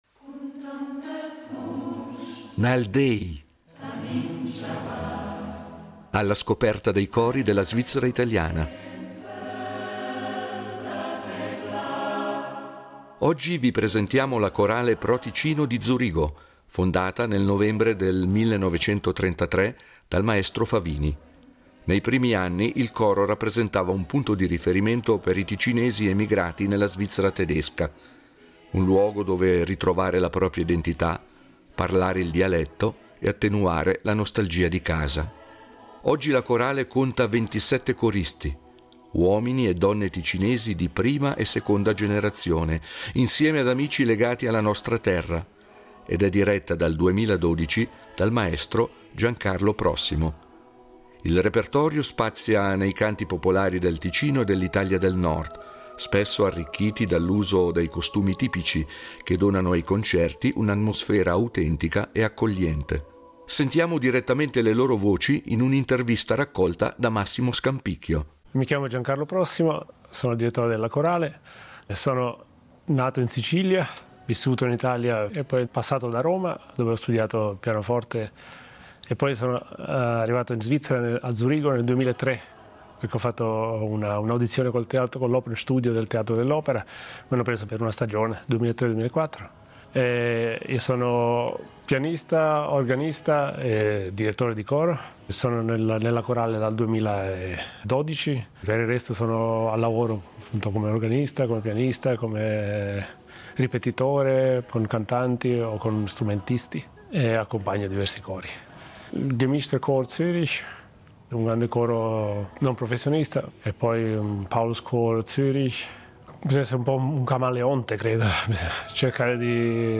Nal déi, cori della svizzera italiana
è un coro di 27 membri
canti popolari